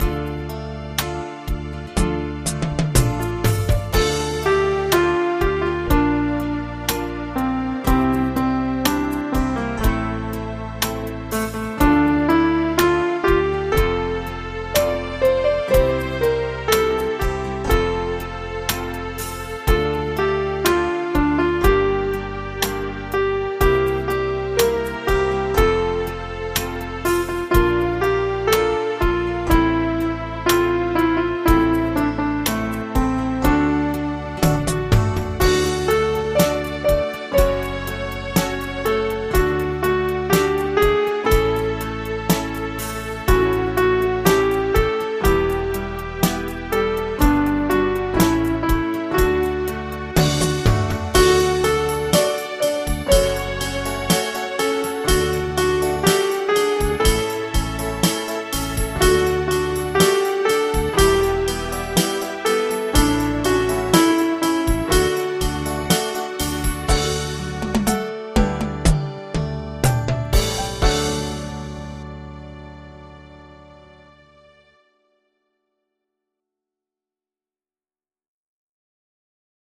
Audio Midi Bè 02: download